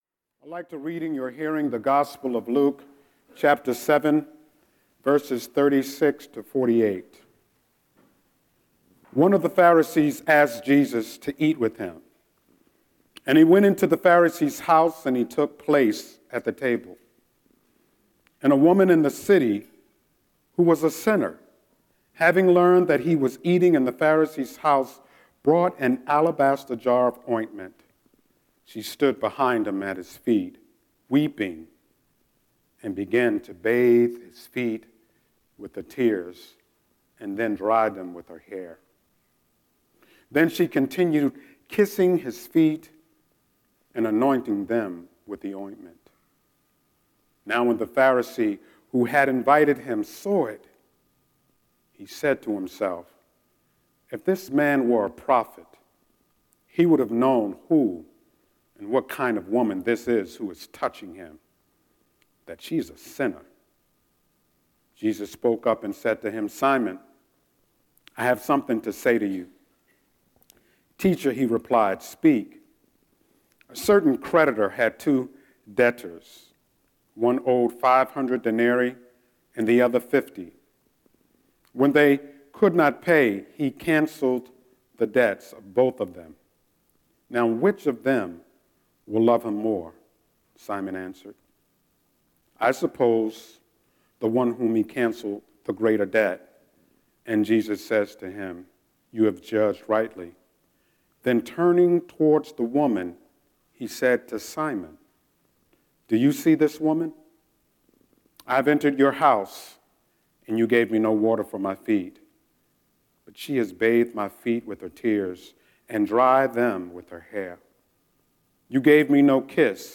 06-12-Scripture-and-Sermon.mp3